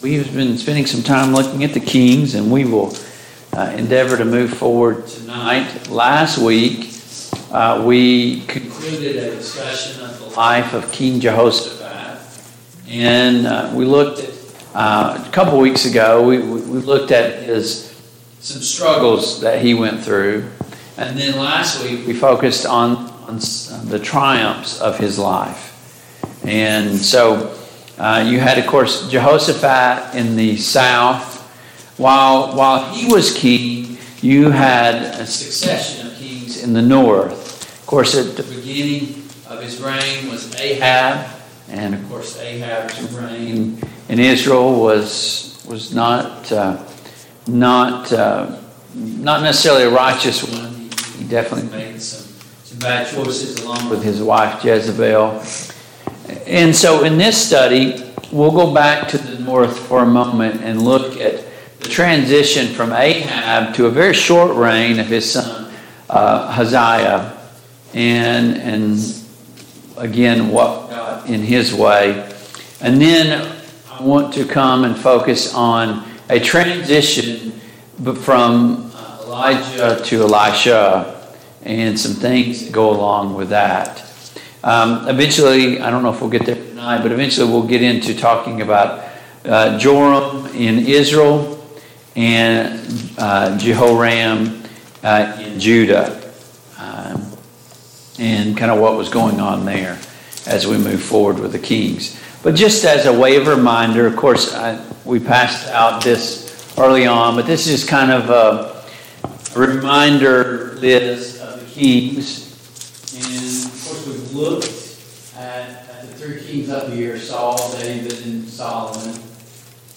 The Kings of Israel Passage: 2 Kings 1, 2 Kings 2 Service Type: Mid-Week Bible Study Download Files Notes « 1.